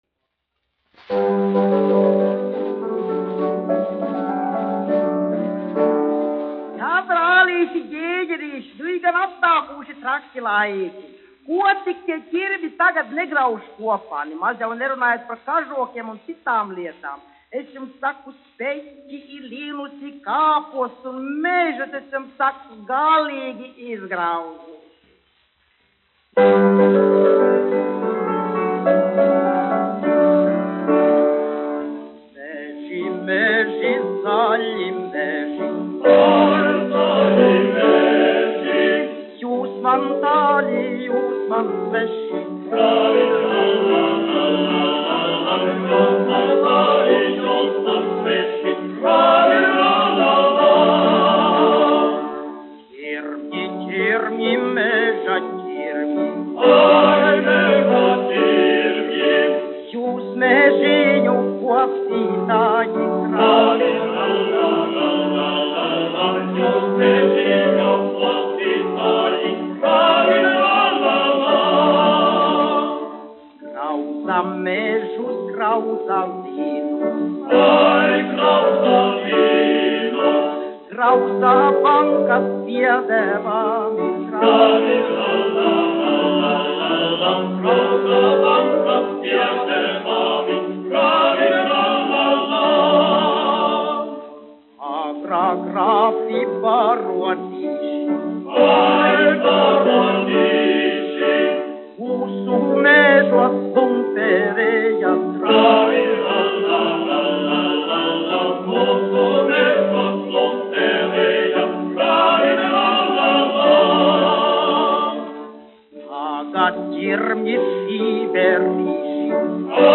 1 skpl. : analogs, 78 apgr/min, mono ; 25 cm
Vokālie seksteti
Humoristiskās dziesmas
Skaņuplate